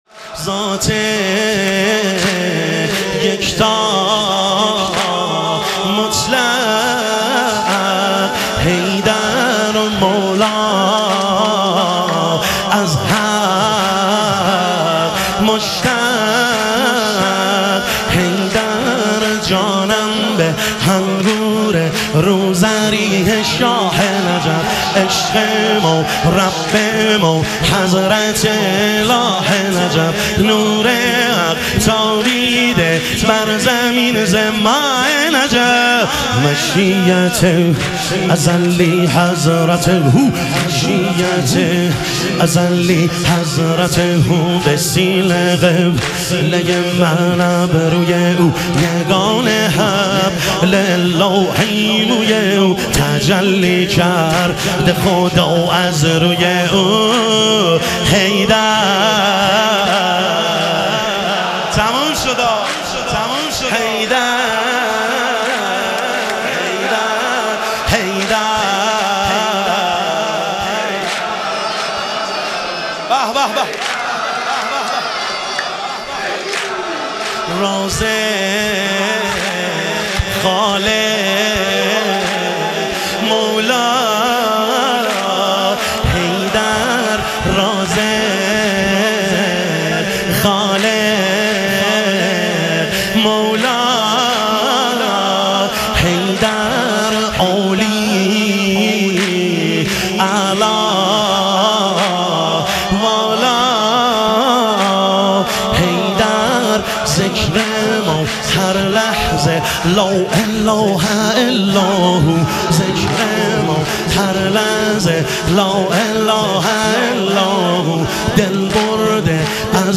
اربعین امیرالمومنین علیه السلام - واحد